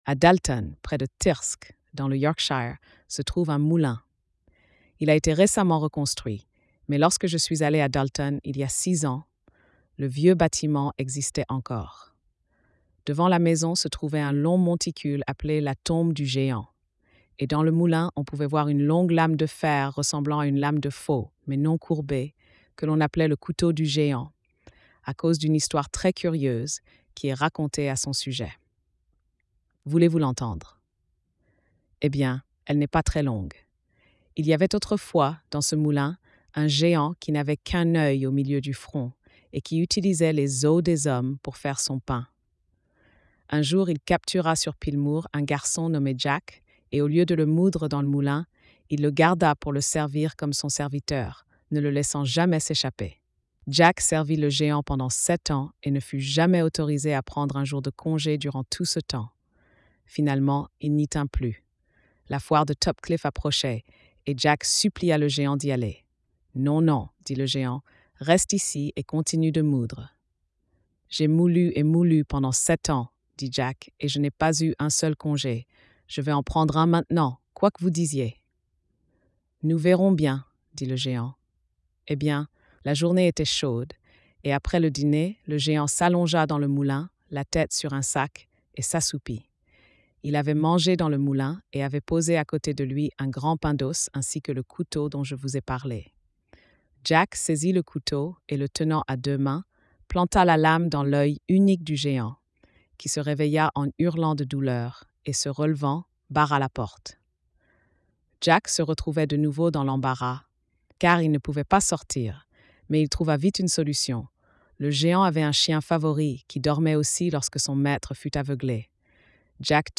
Conte de Grimm
🎧 Lecture audio générée par IA